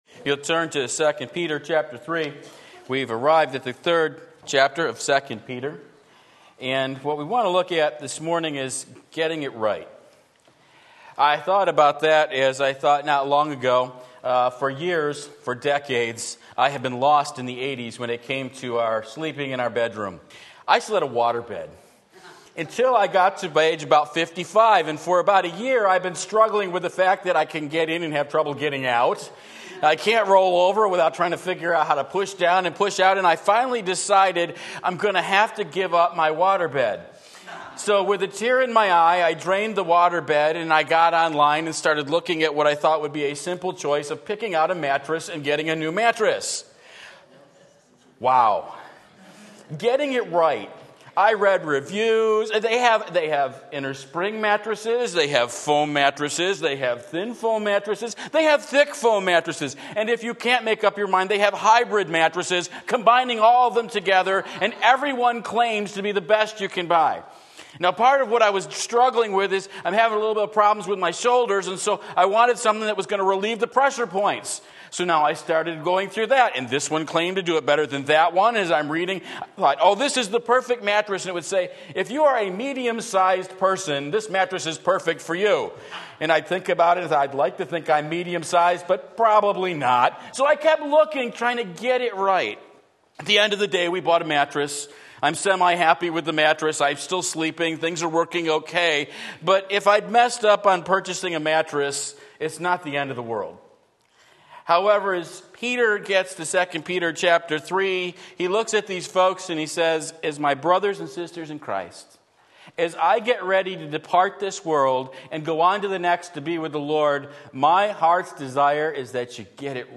Sermon Link
Getting It Right 2 Peter 3:1-13 Sunday Morning Service, September 1, 2019 Stirred Up!